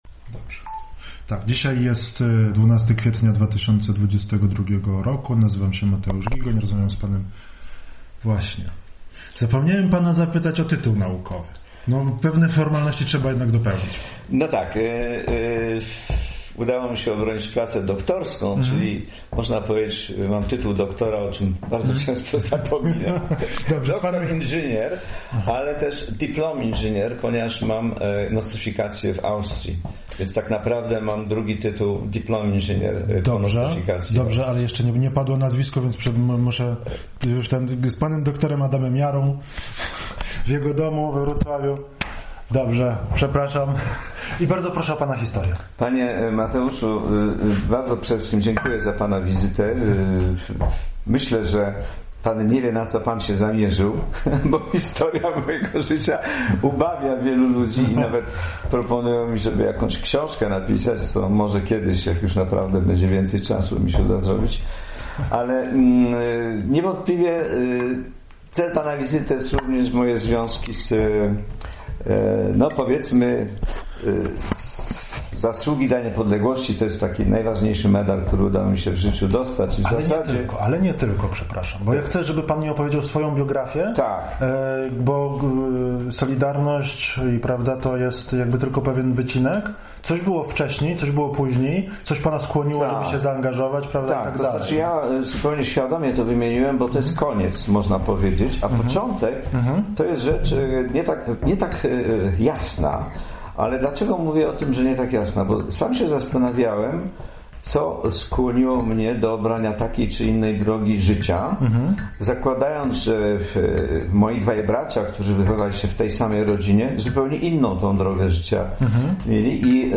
Data przeprowadzenia wywiadu